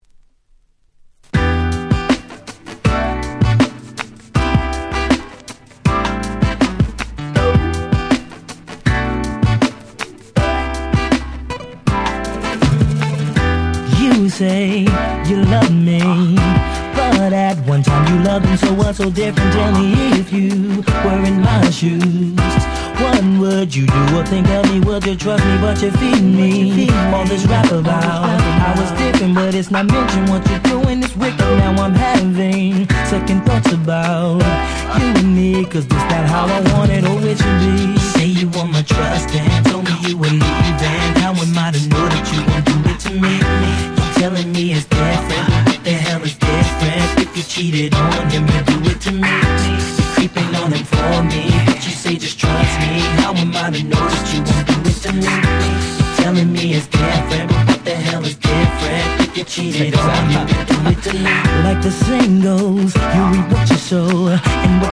当時HotだったR&B4曲をこの盤オンリーのNice Remixに！！